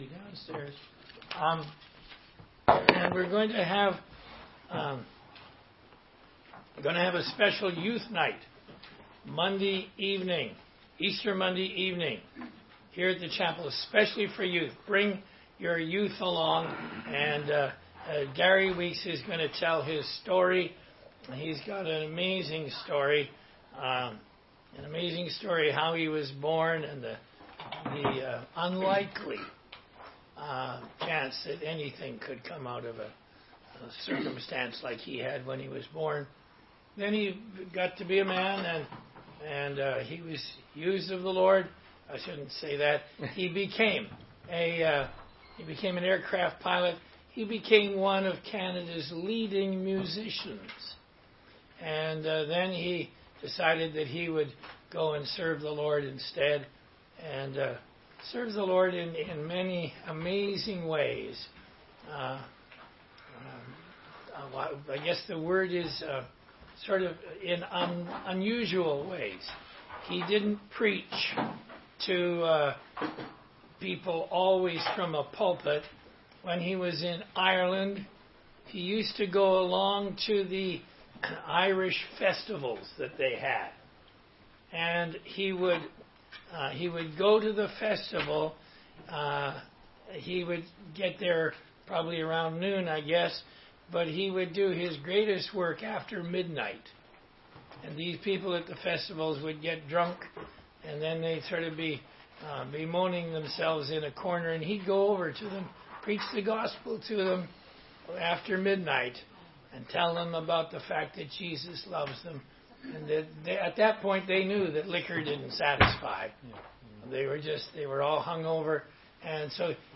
16街讲道录音 - 罗马书8章29节-9章5节